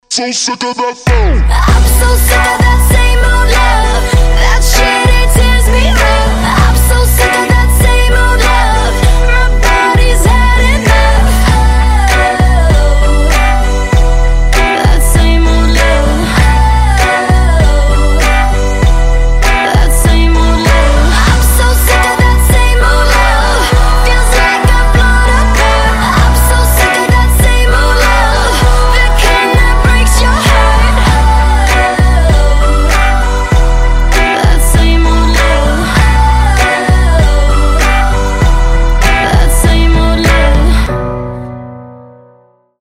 Танцевальные
Метки: поп, dance, RnB,